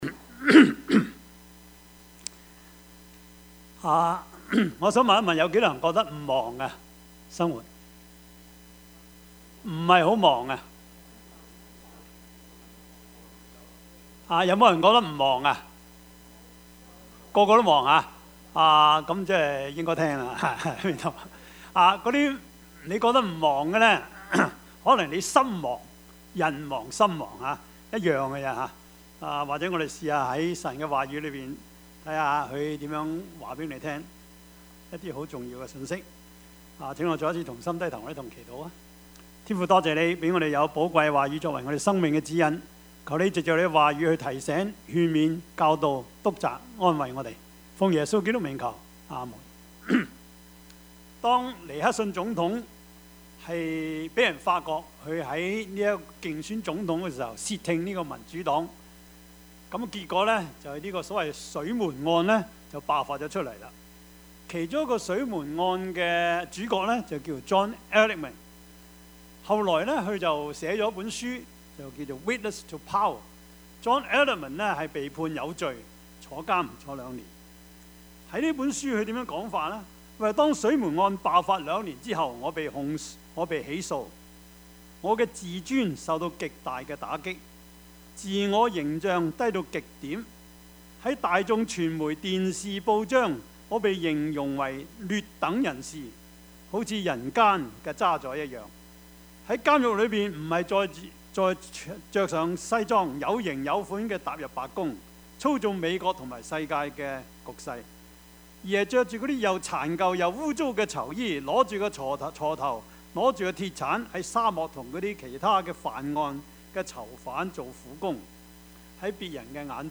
Topics: 主日證道 « 大的樣子 聖與俗 »